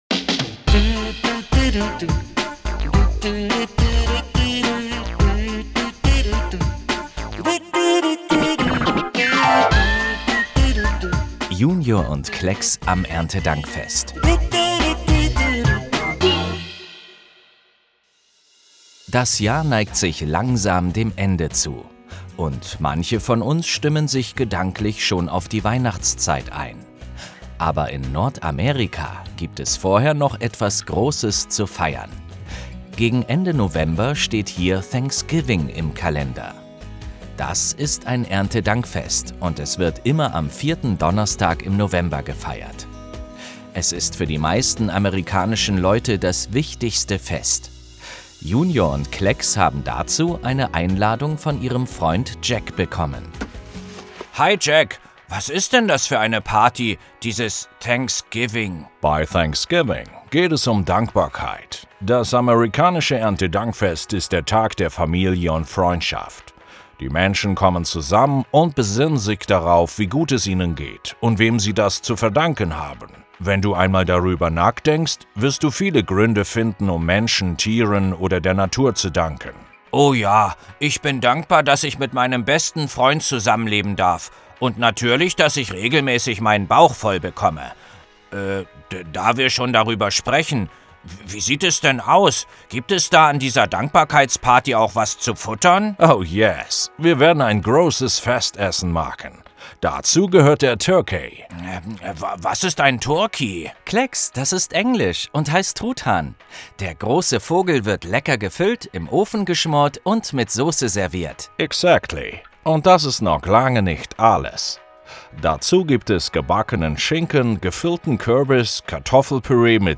Hörspiel Junior & Klexx November 2024 - JUNIOR Schweiz